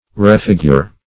Refigure \Re*fig"ure\ (r?*f?g"?r)